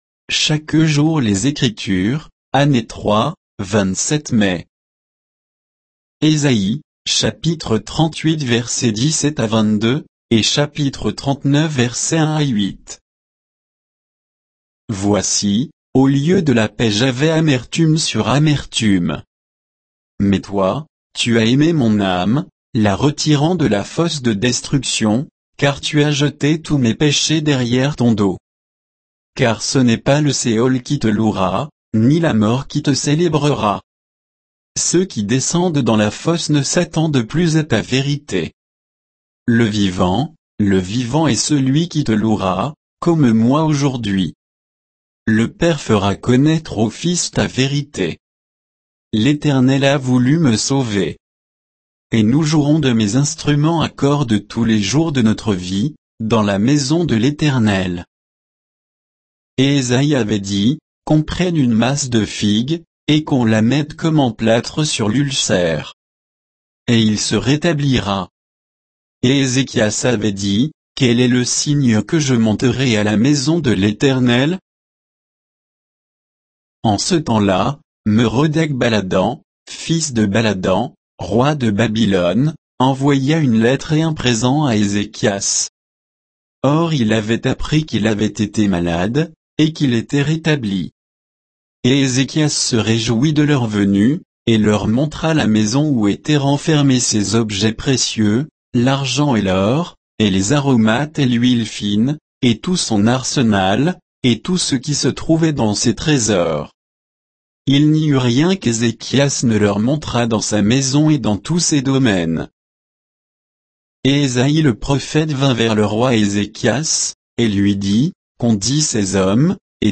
Méditation quoditienne de Chaque jour les Écritures sur Ésaïe 38, 17 à 39, 8